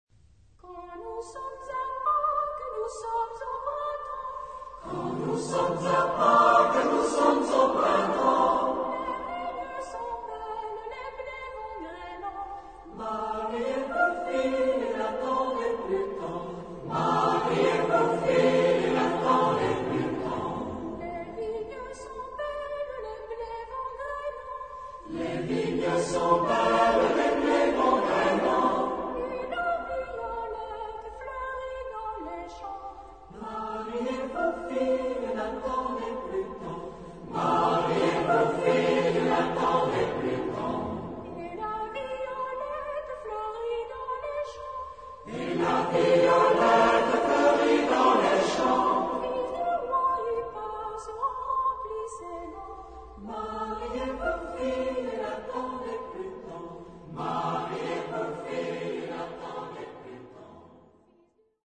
Genre-Style-Form: Popular ; Traditional ; Partsong ; Secular
Type of Choir: SATB  (4 mixed voices )
Tonality: F major